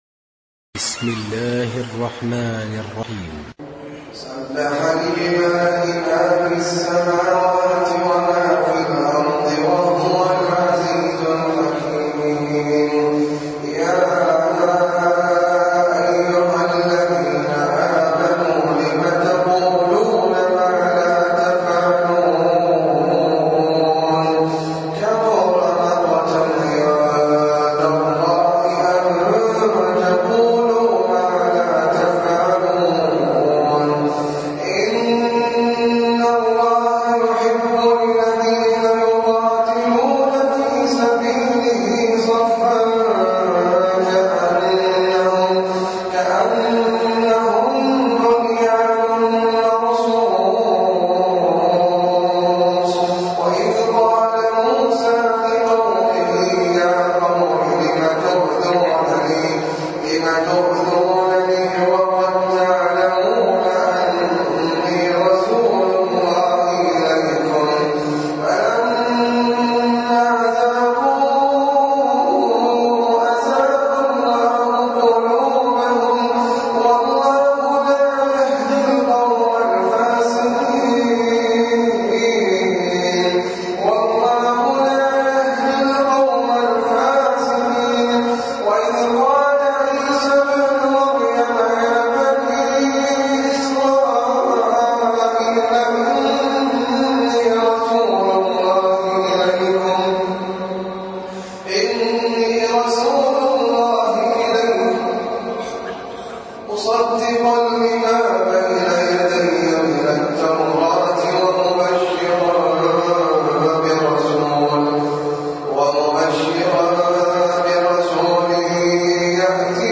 سورة الصف - المصحف المرتل (برواية حفص عن عاصم)
جودة عالية